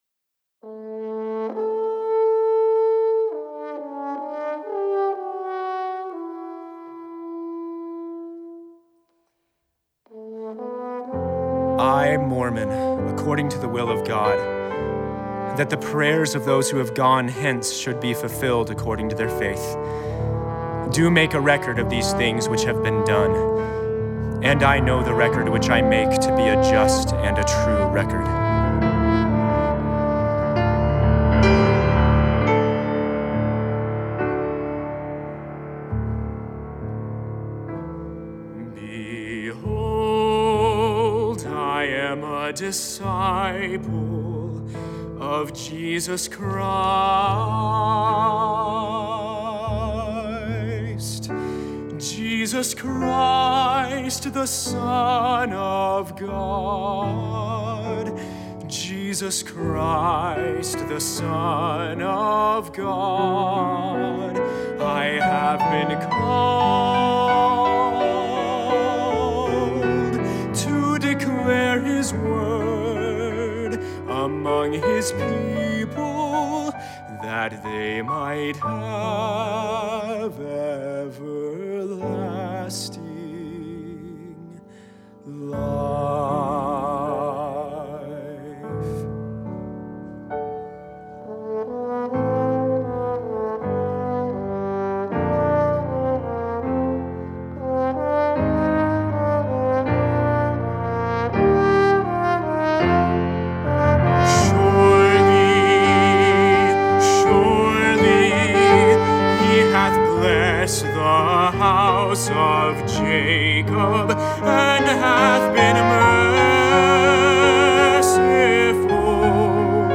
Baritone, Horn, SATB, and Piano